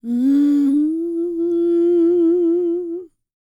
E-CROON P307.wav